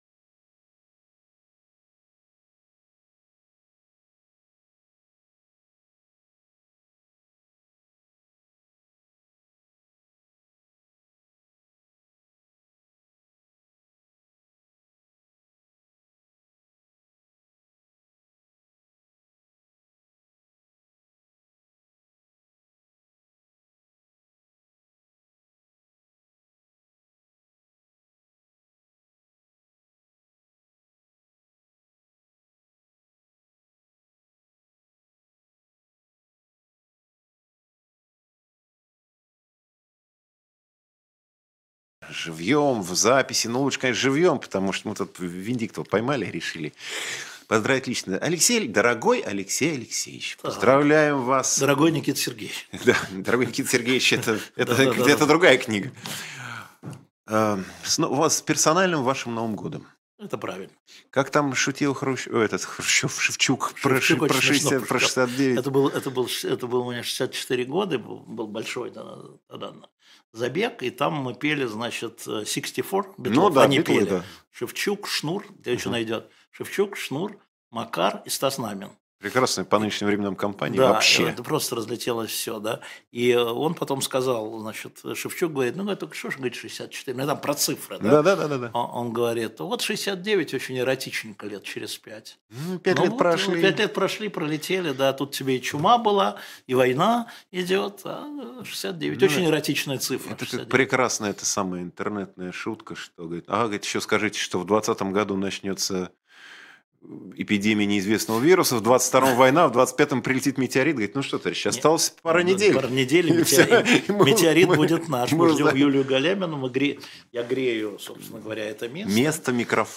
Эфир ведёт Антон Орехъ